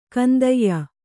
♪ kandayya